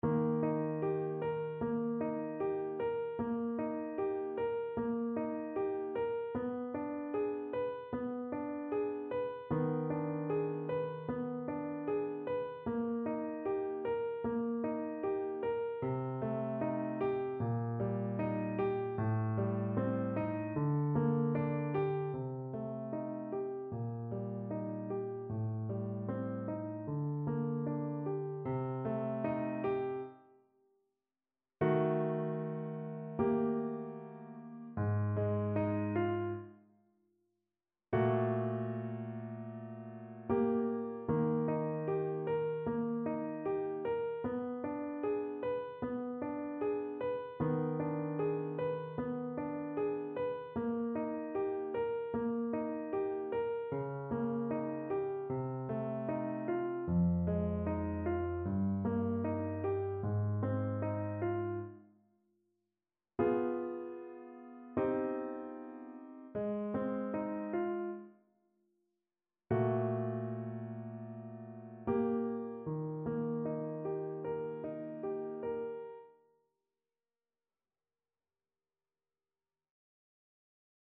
Grieg: Arietta (na klarnet i fortepian)
Symulacja akompaniamentu